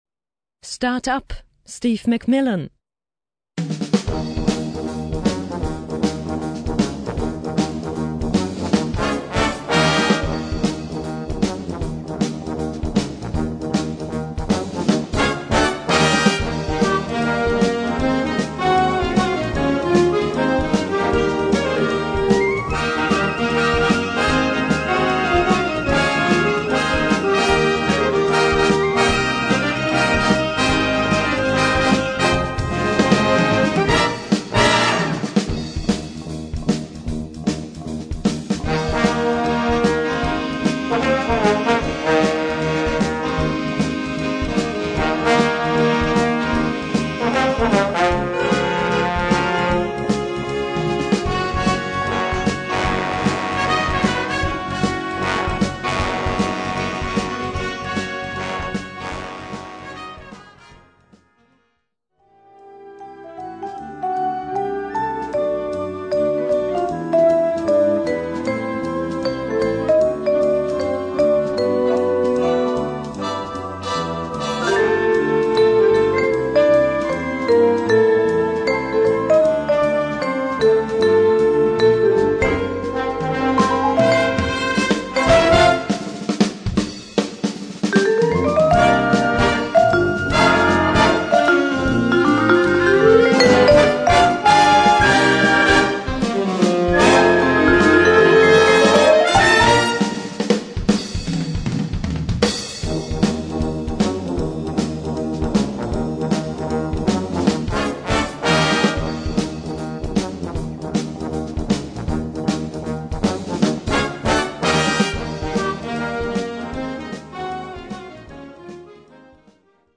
Gattung: Eröffnungswerk
Besetzung: Blasorchester
swingende Eröffnungsnummer